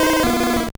Cri de Salamèche dans Pokémon Or et Argent.